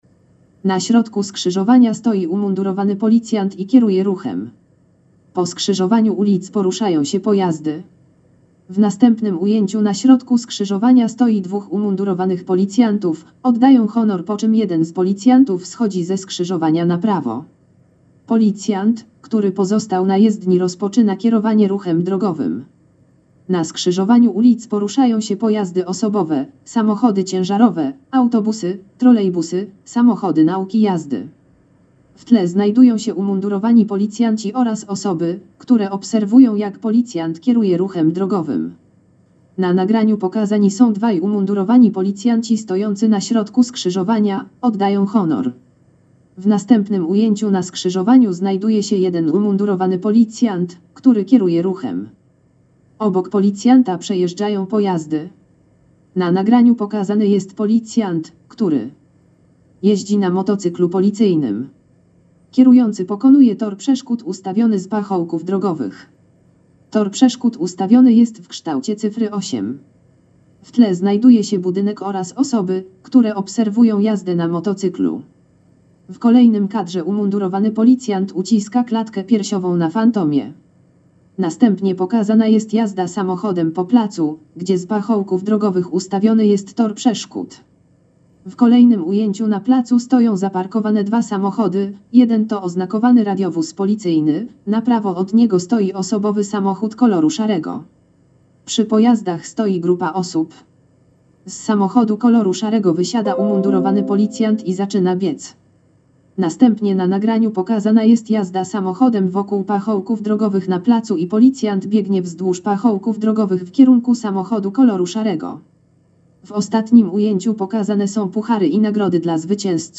Nagranie audio Audiodeskrypcja filmu "Policjant Ruchu Drogowego"